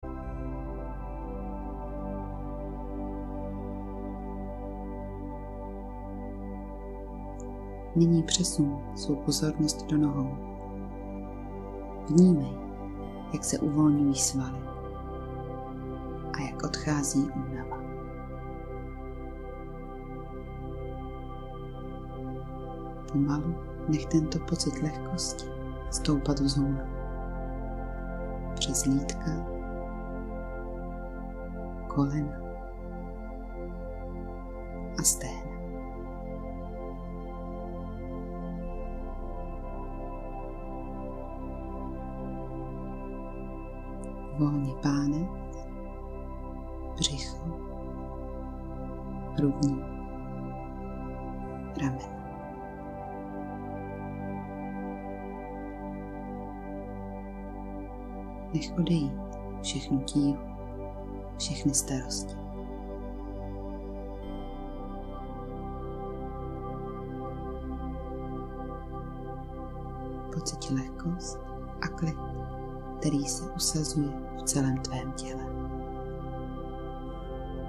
Nahraju pro vás jemnou meditační nebo afirmativní nahrávku (ženský hlas)
• Nahrávku ženského hlasu (klidného, jemného charakteru)
Nahrávám v domácím prostředí s důrazem na čistotu zvuku a příjemný přednes.